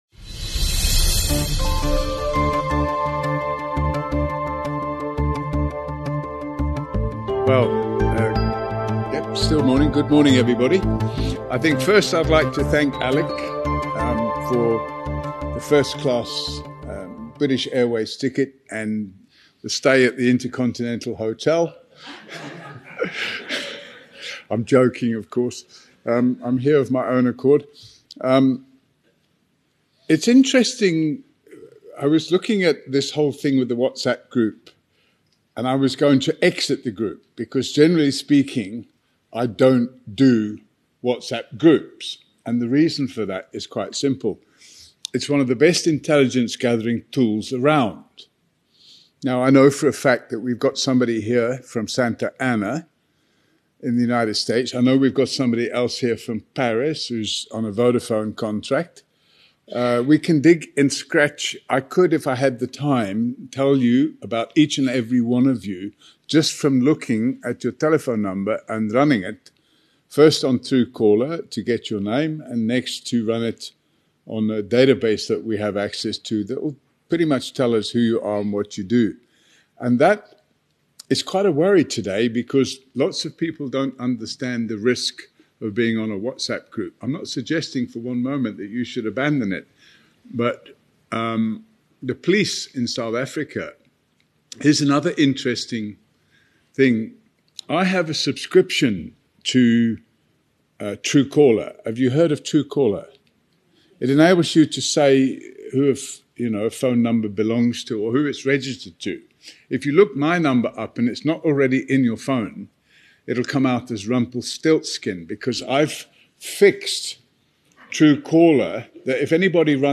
17 May BNC London